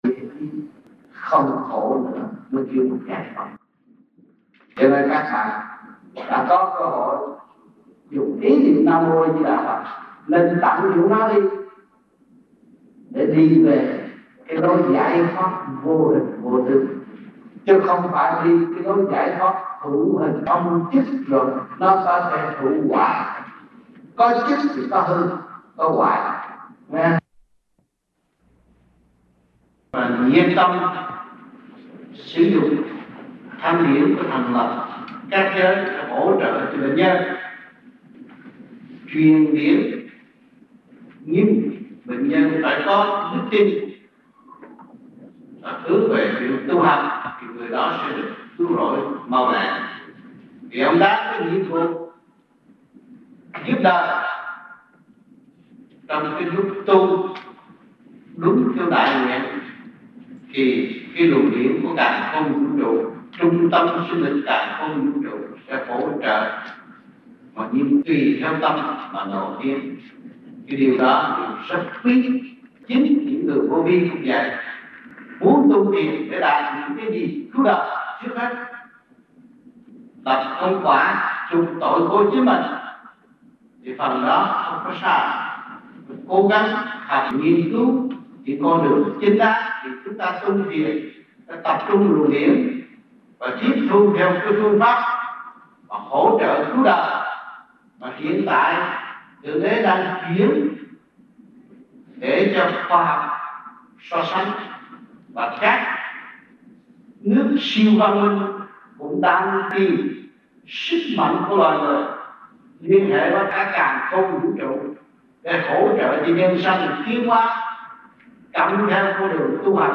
Băng Giảng Và Vấn Đạo Tại Những Đại Hội Vô Vi Quốc Tế